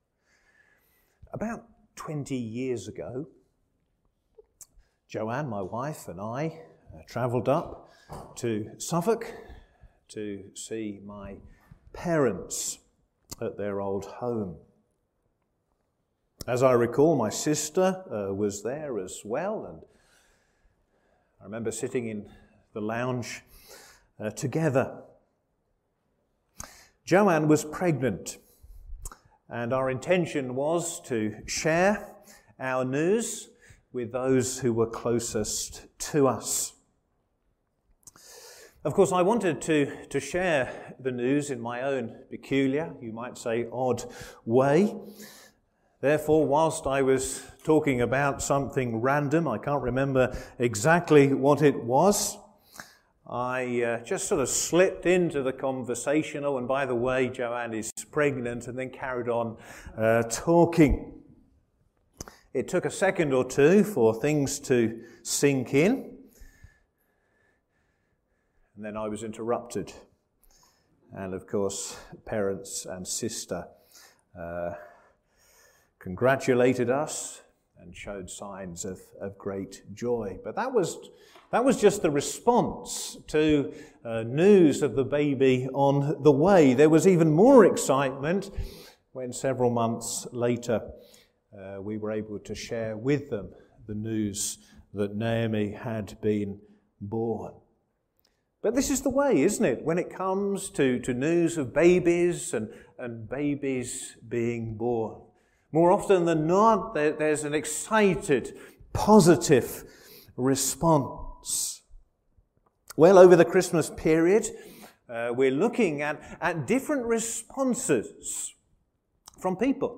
Sermons
Service Morning